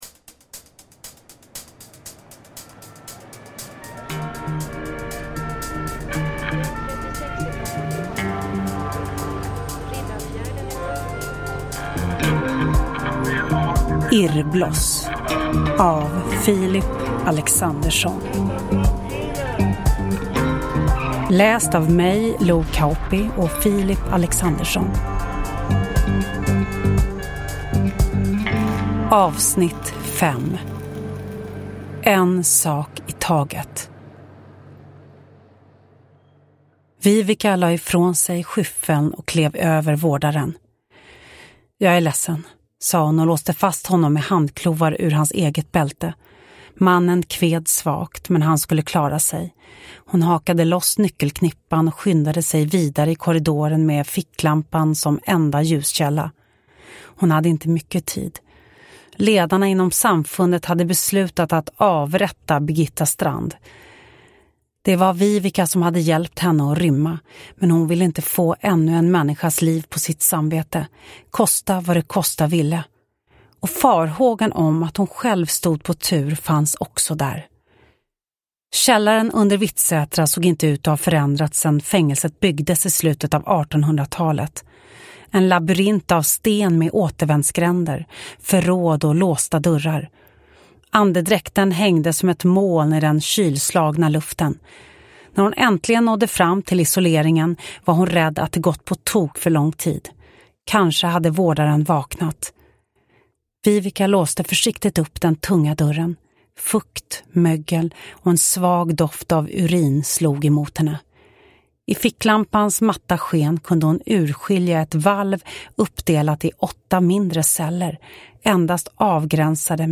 Hidden S1A5 Irrbloss : En sak i taget – Ljudbok – Laddas ner